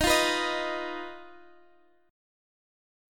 D#sus2#5 chord